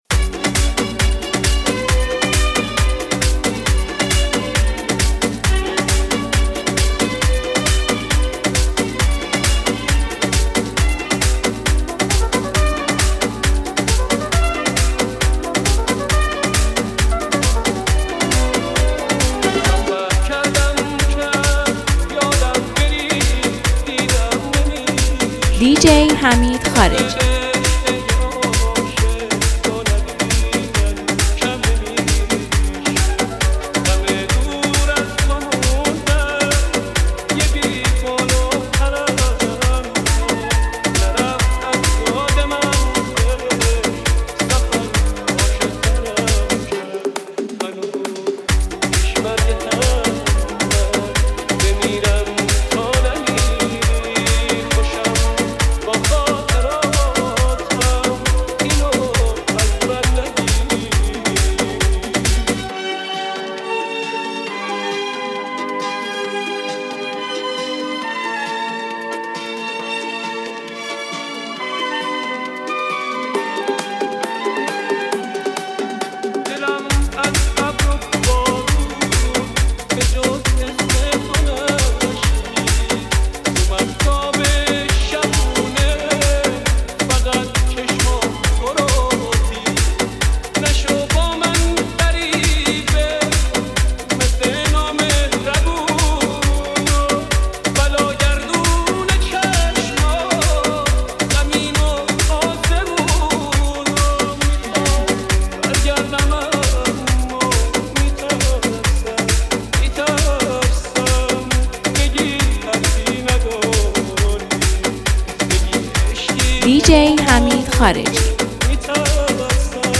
یه ریمیکس فوق‌العاده و خاطره‌انگیز برای شما آماده کردیم!